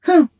Kara-Vox_Attack2_jp.wav